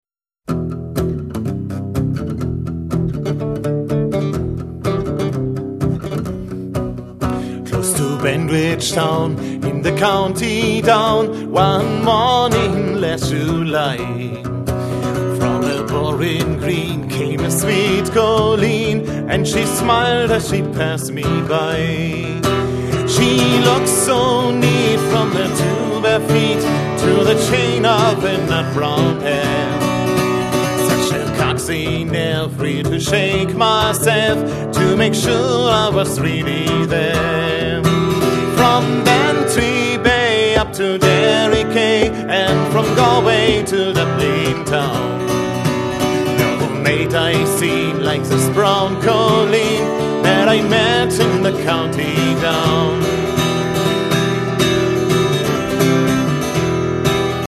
Eine klare Stimme begleitet von seiner Gitarre